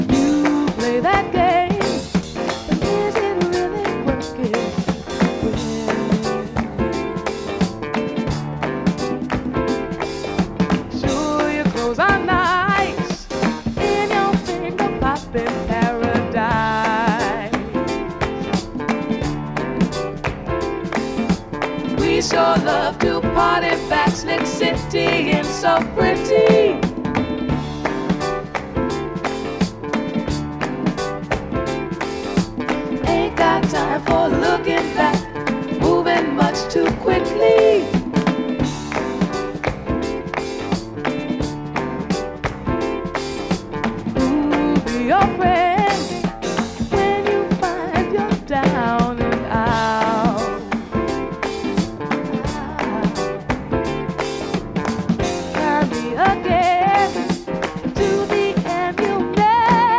ジャンル(スタイル) RARE GROOVE / JAZZ FUNK / FUSION